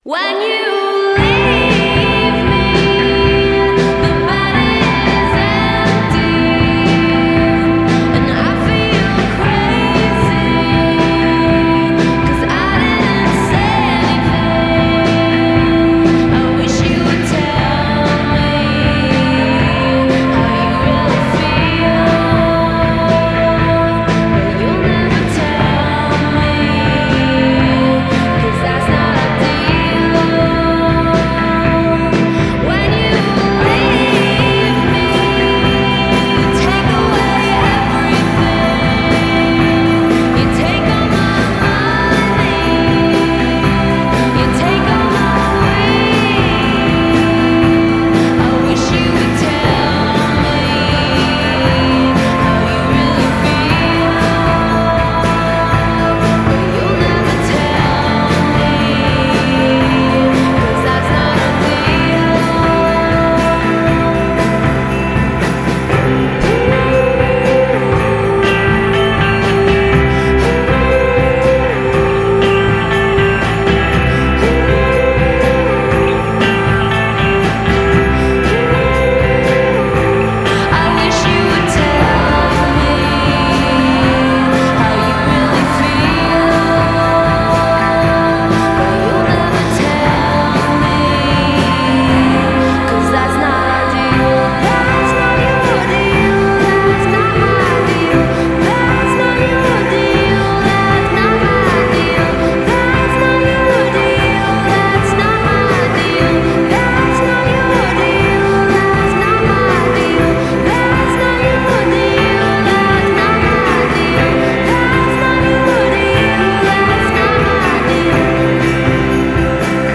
modern beach pop for late summer